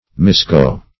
misgo - definition of misgo - synonyms, pronunciation, spelling from Free Dictionary Search Result for " misgo" : The Collaborative International Dictionary of English v.0.48: Misgo \Mis*go"\ (m[i^]s*g[=o]"), v. i. To go astray.
misgo.mp3